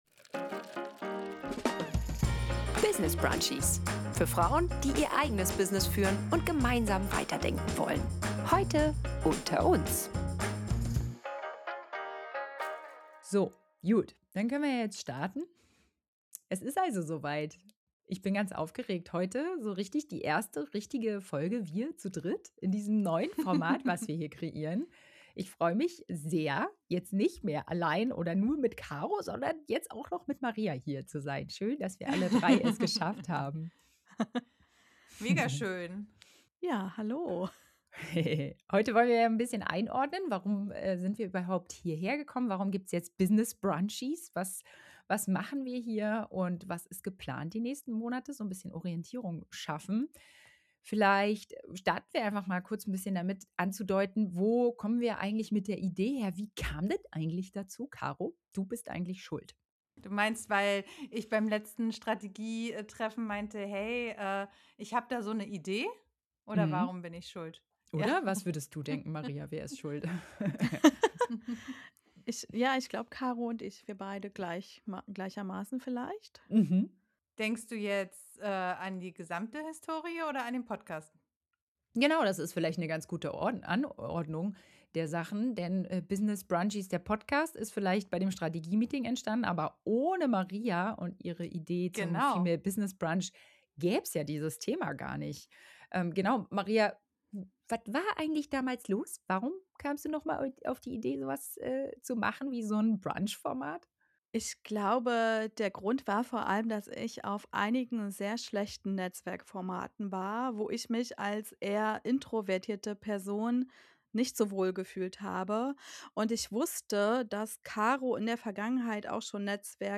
In dieser Folge sprechen wir – zum ersten Mal zu dritt – über die echten Beweggründe hinter dem Relaunch.